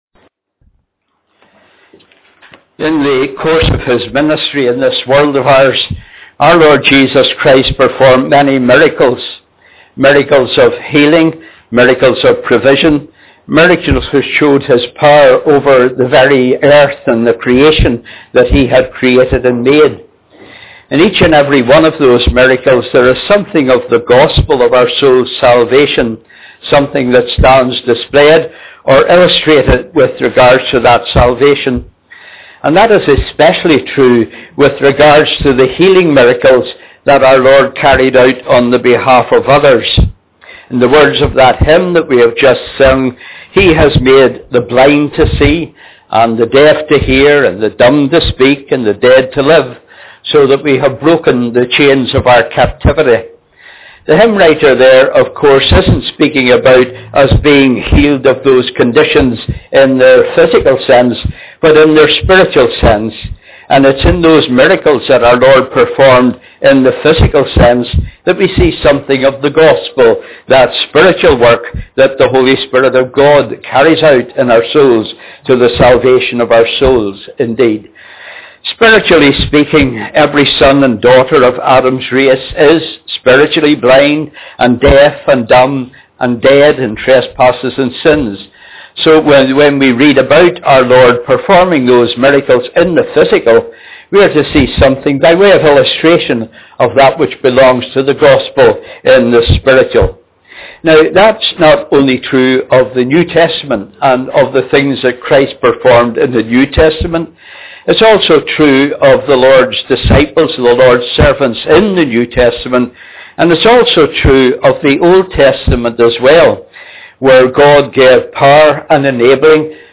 This was a combined service